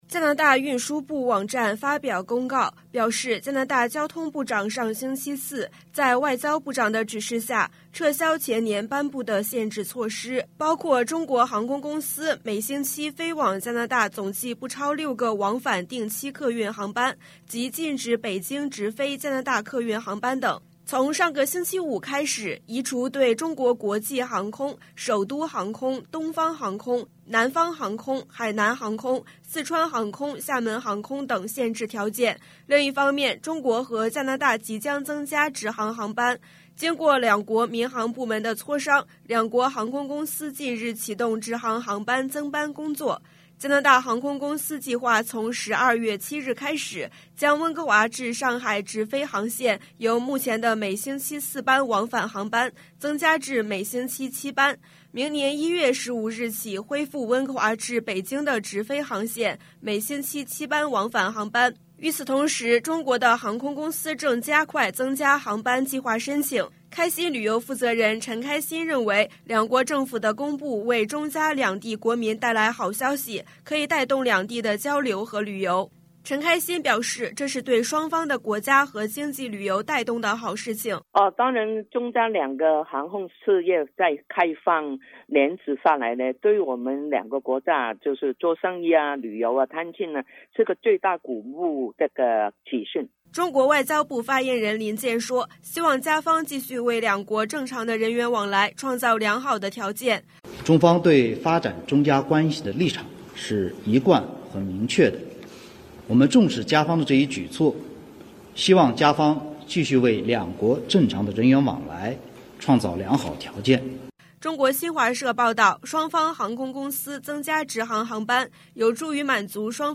news_clip_21092_mand.mp3